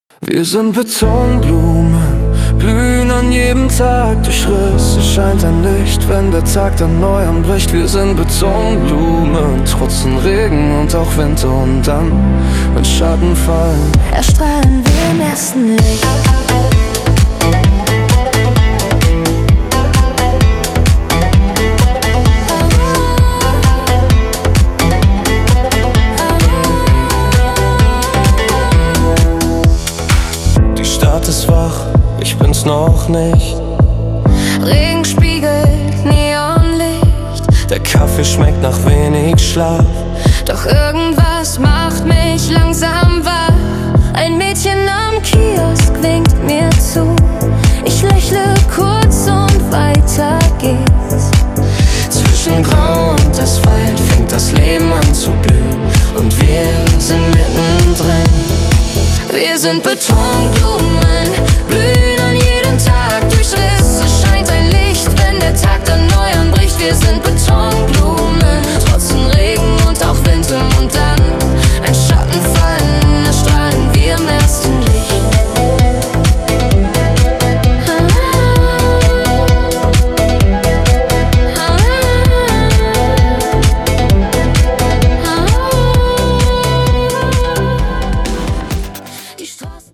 Und wir lieben es, zu zweit zu singen.